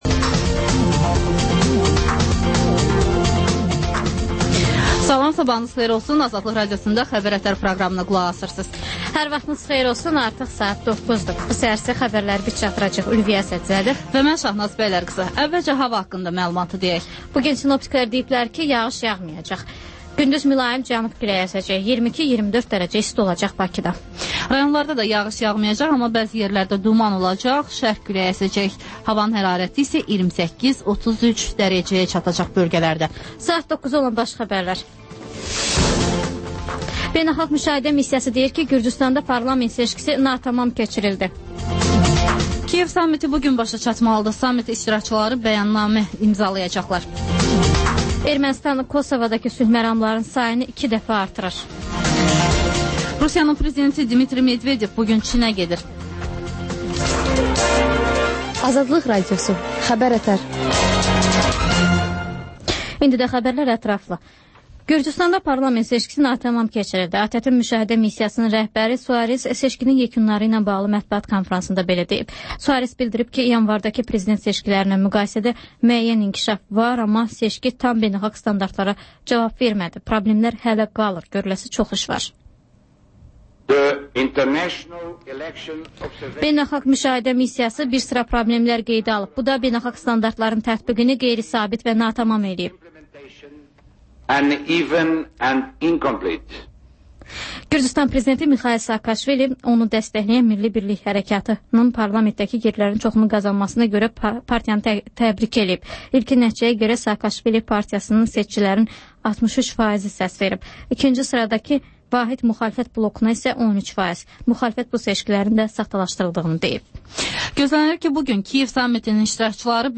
Xəbər-ətər: xəbərlər, müsahibələr və 14-24: Gənclər üçün xüsusi veriliş